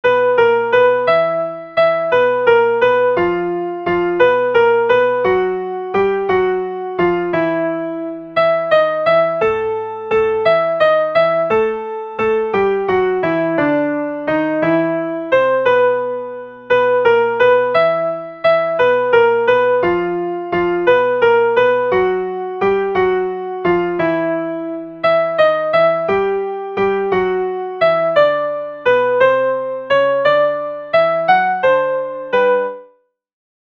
Le chœur des invités
Alti
elle_danse_alti.mp3